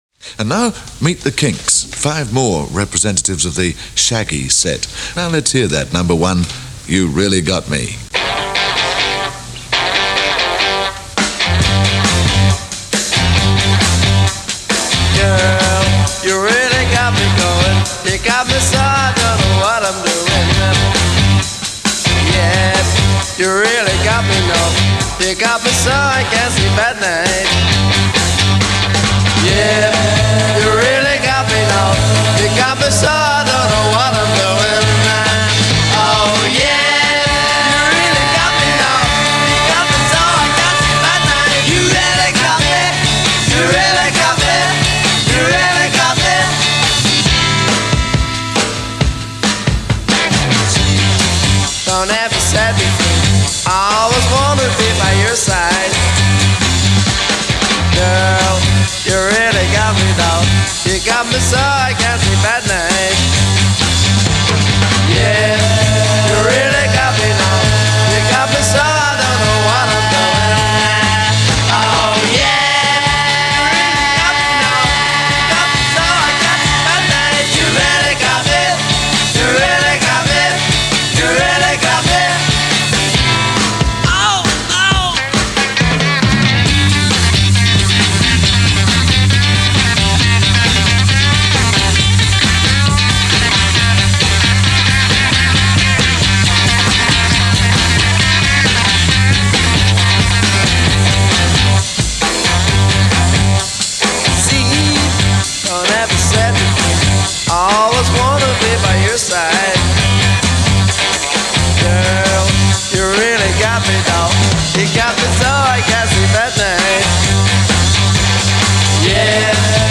features a jagged, distorted guitar riff